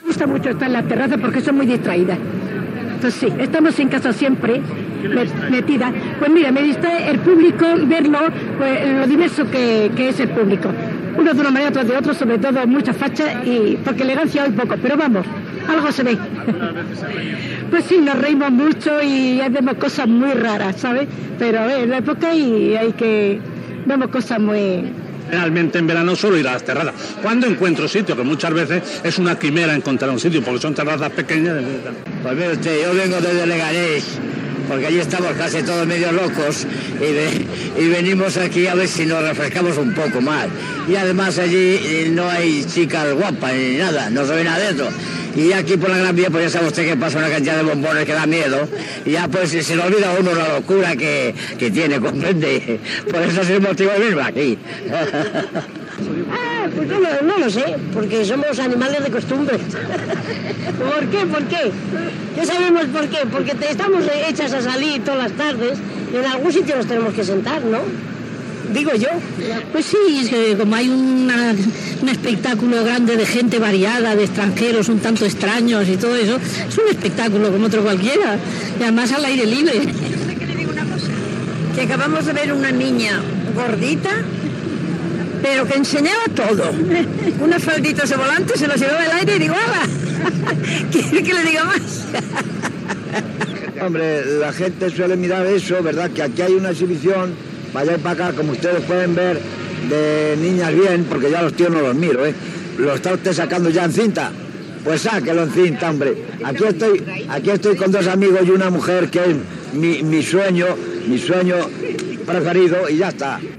Reportatge fet a les terrasses dels bars de la Gran Vía de Madrid
Entreteniment